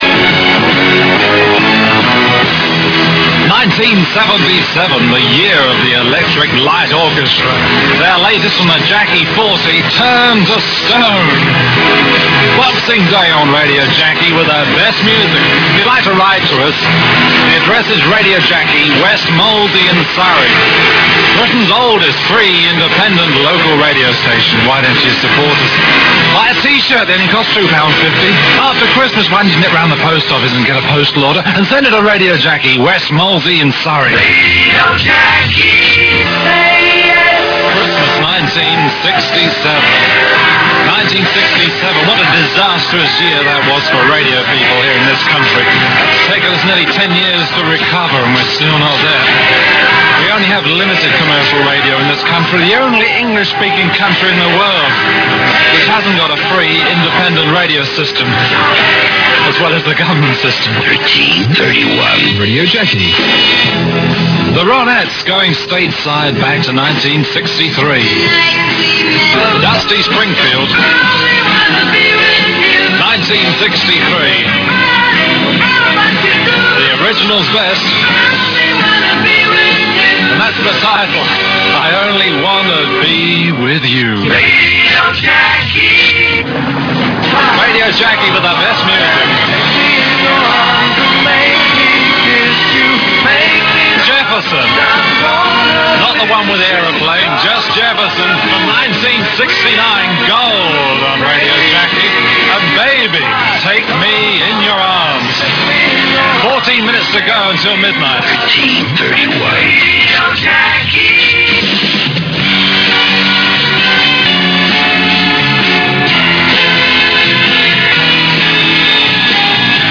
from one of the legendary Christmas broadcasts.